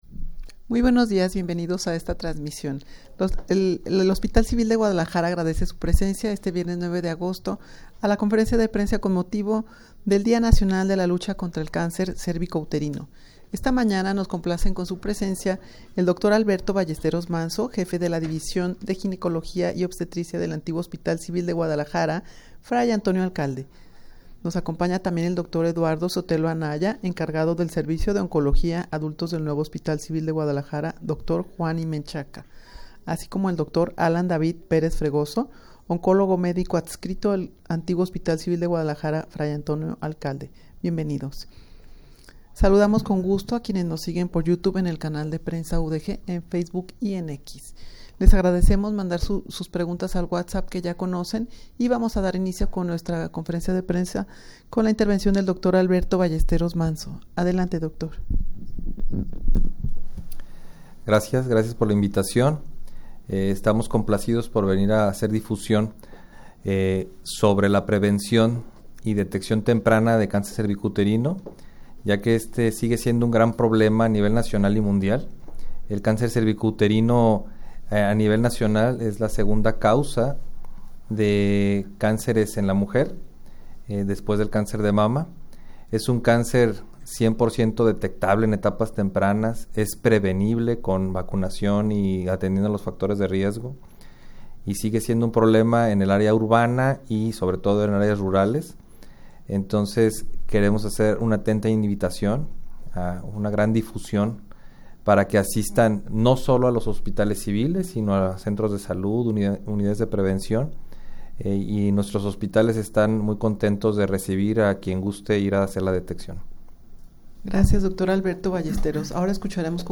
Con detección oportuna, la enfermedad es cien por ciento curable Audio de la Rueda de Prensa 19.07 MB El cáncer cervicouterino se mantiene como un problema de salud a nivel nacional y mundial.
rueda-de-prensa-con-motivo-del-dia-nacional-de-lucha-contra-el-cancer-cervicouterino.mp3